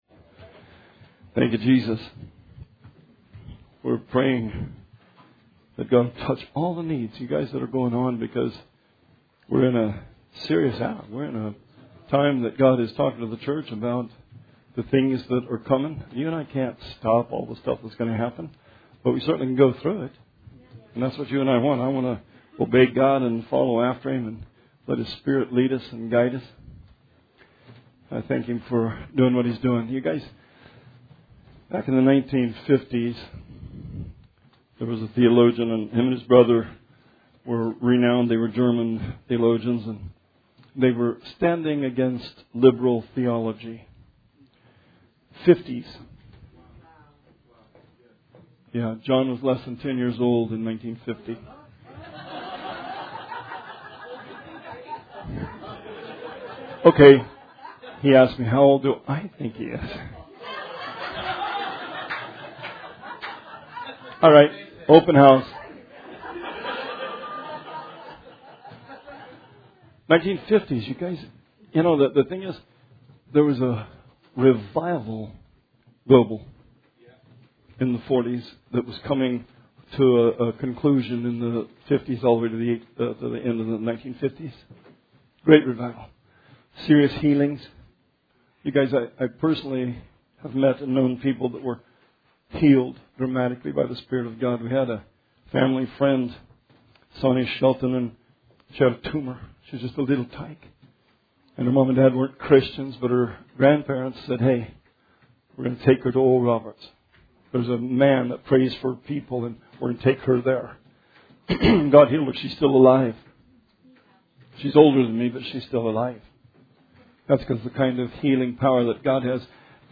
Sermon 3/1/20